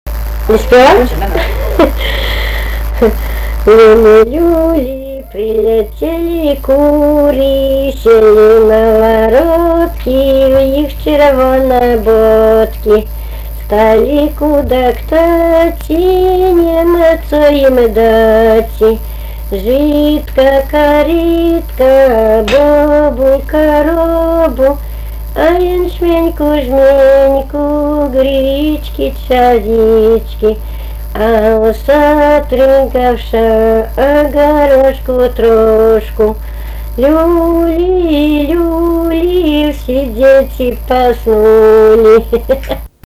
vokalinis